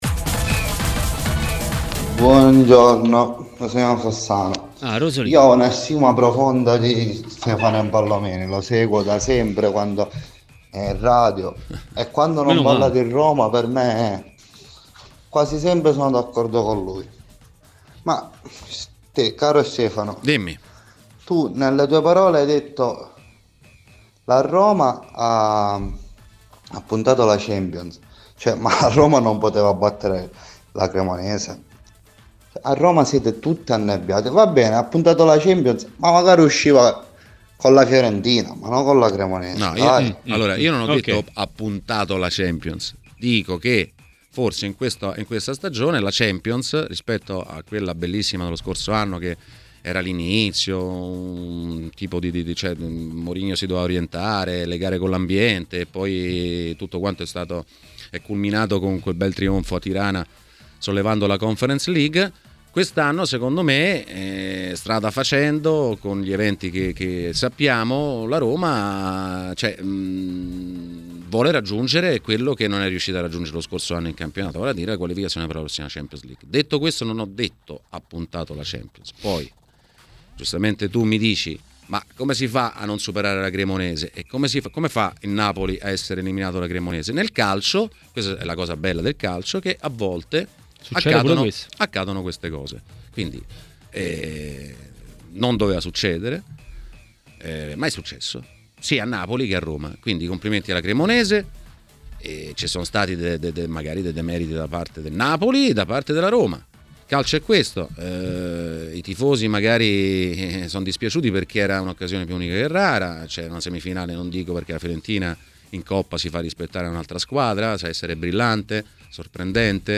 ha parlato di Roma e non solo a Maracanà, nel pomeriggio di TMW Radio.